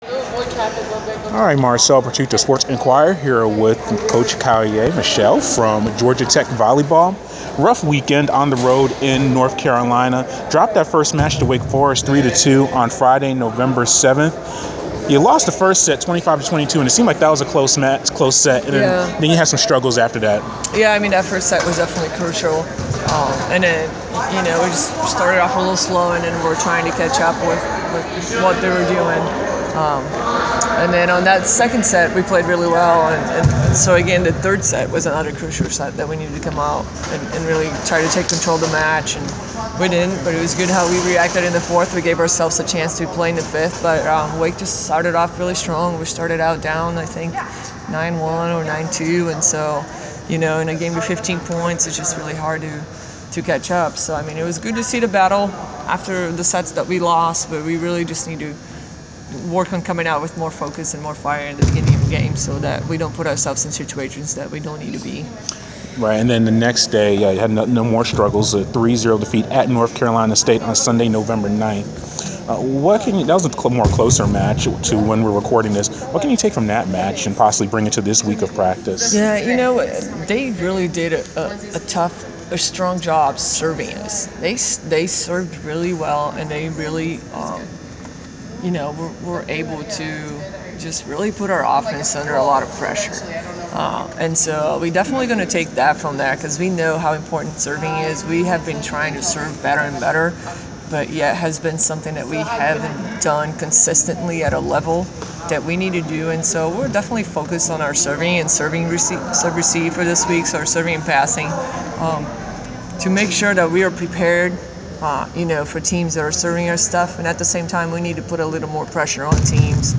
Inside the Inquirer: Interview